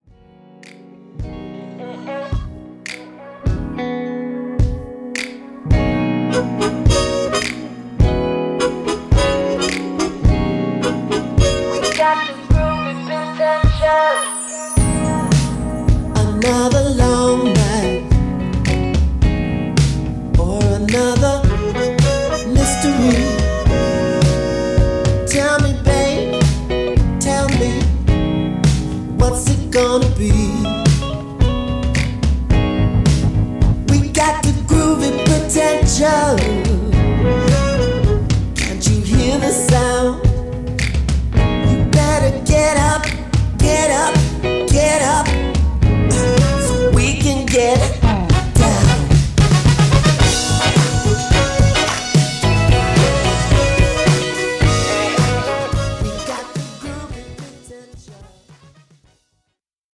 ホーム ｜ SOUL / FUNK / RARE GROOVE / DISCO > SOUL
’70年代ブラック・ミュージックの良質なエッセンスがストレートに盛り込まれた好曲が揃った